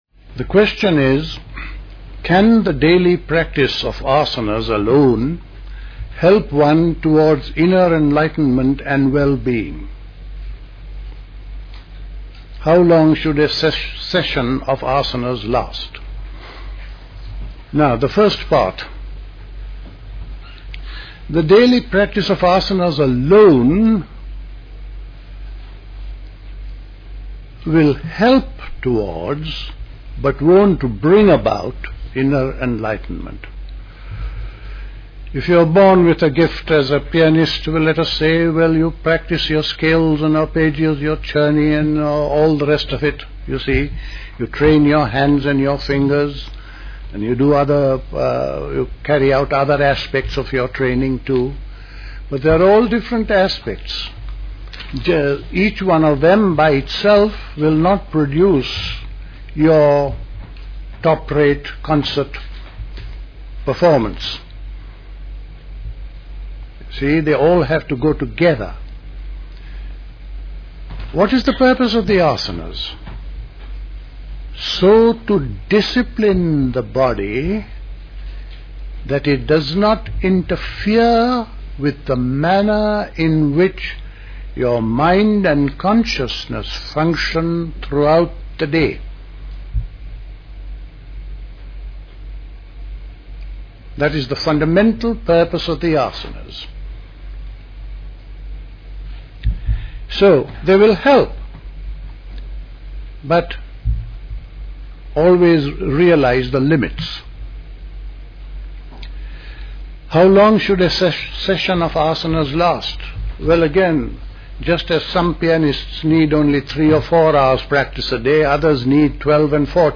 A talk
at Missenden Abbey, Great Missenden, Buckinghamshire